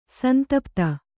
♪ santapta